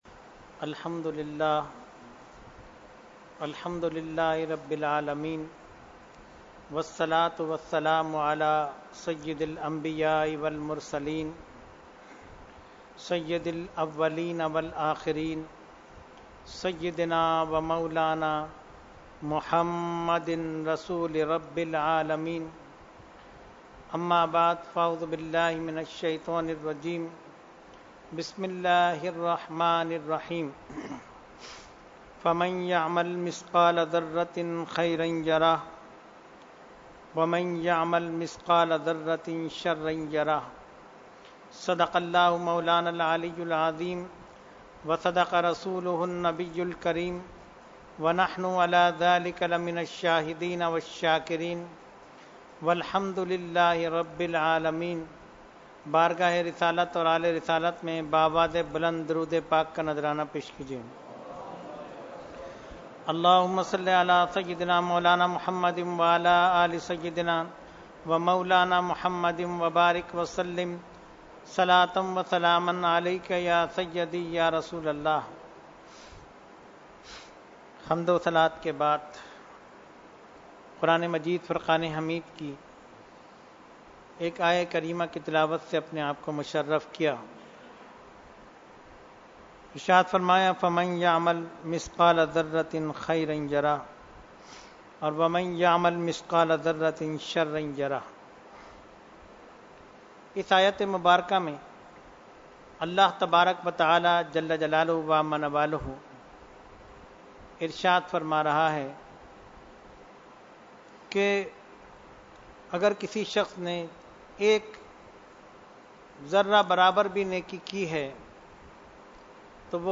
Weekly Tarbiyati Nashist held on 2/12/2018 at Dargah Alia Ashrafia Ashrafabad Firdous Colony Karachi.
Category : Speech | Language : UrduEvent : Weekly Tarbiyati Nashist